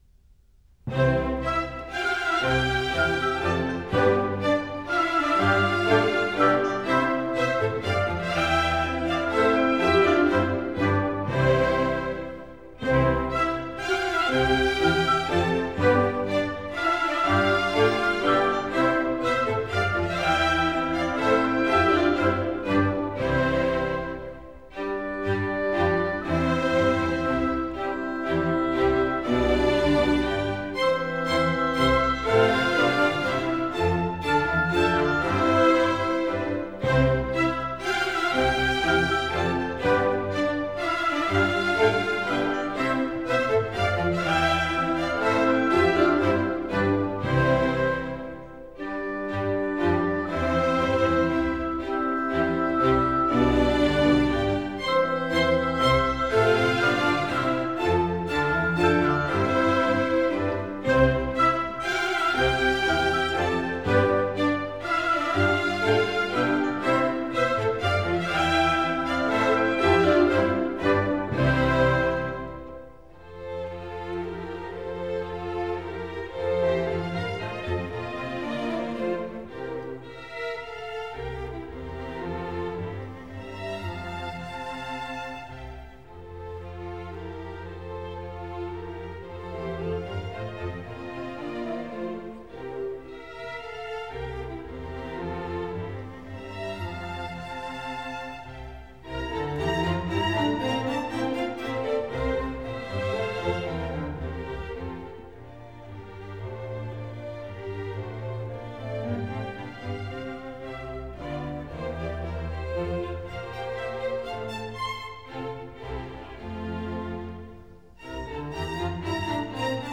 » 1 - Symphonies